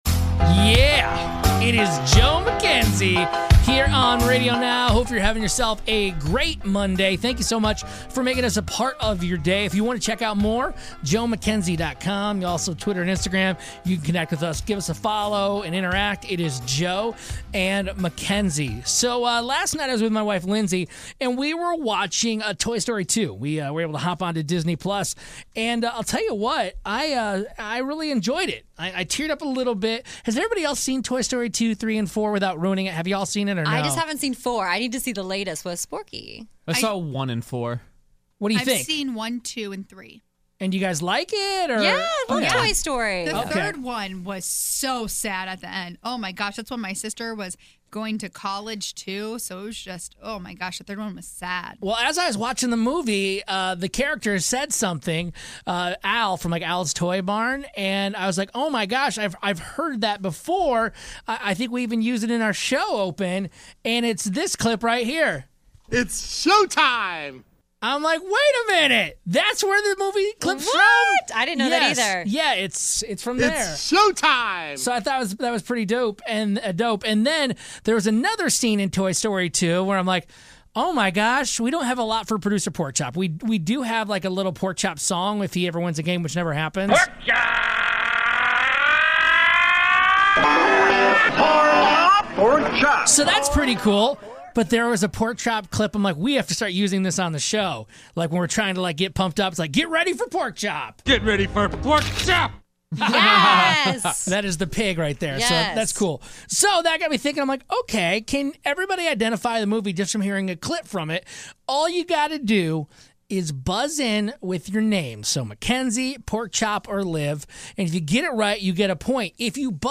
plays movie clips and we've gotta guess the movie their from!